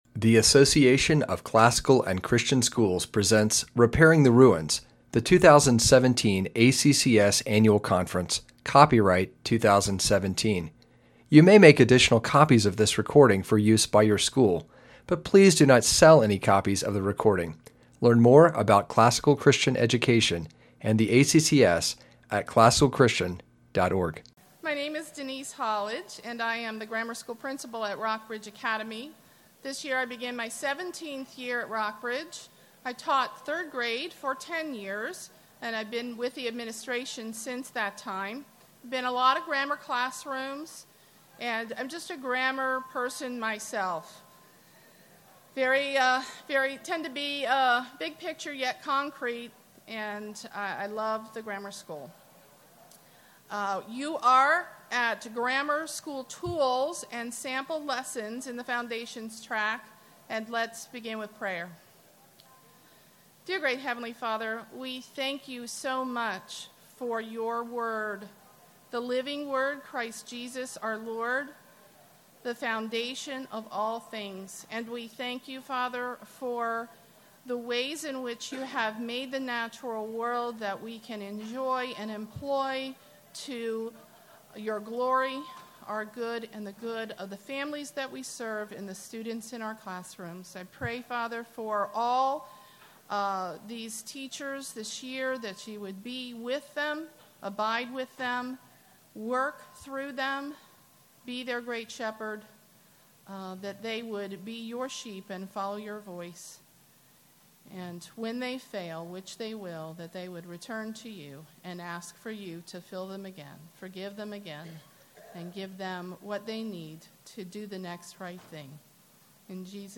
Jan 9, 2019 | Conference Talks, Foundations Talk, K-6, Library, Media_Audio, Teacher & Classroom | 0 comments
We’ll help you get ready with specific tools for the classroom and some sample lessons for pre-K through 6th. Speaker Additional Materials The Association of Classical & Christian Schools presents Repairing the Ruins, the ACCS annual conference, copyright ACCS.